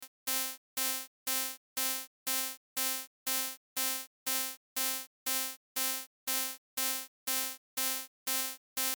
以下の例では、120bpm、すなわち毎分120発、つまり0.5秒ごとの繰り返しとなる。